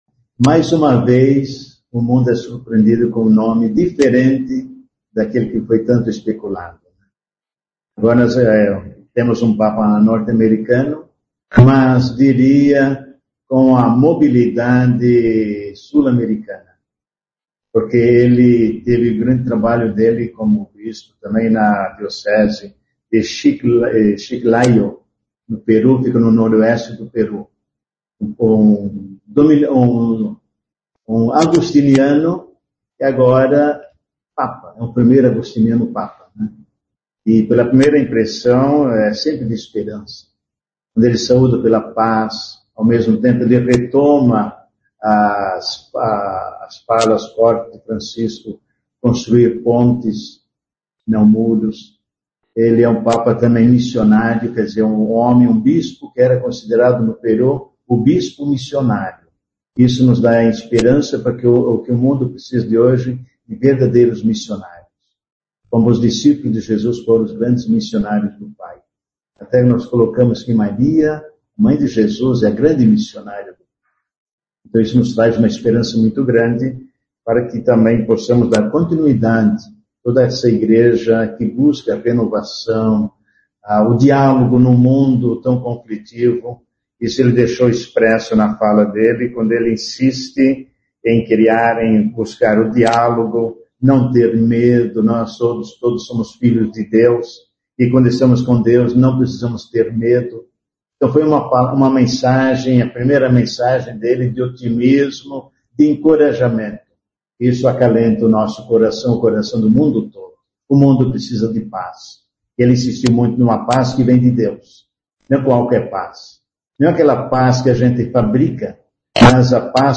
Em entrevista coletiva, o arcebispo de Maringá Dom Frei Severino Clasen comentou a eleição do Papa Leão XIV nesta quinta-feira (8).